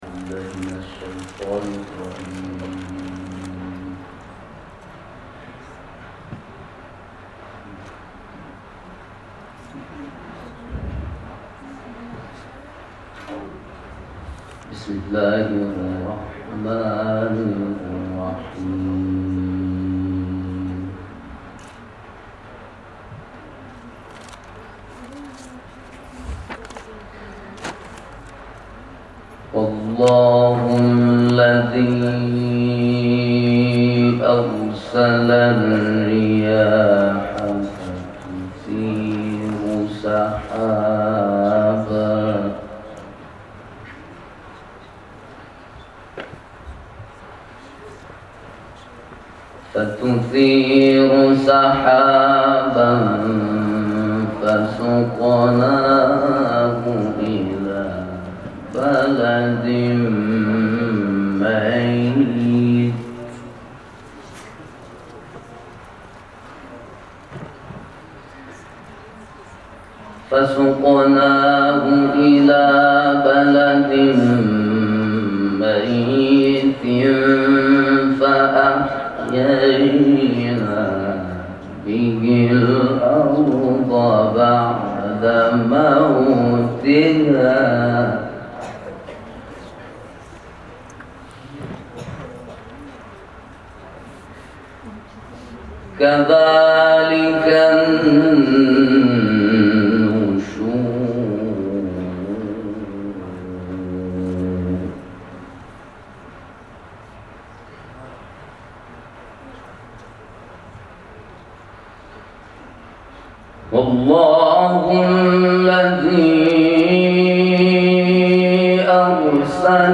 جدیدترین تلاوت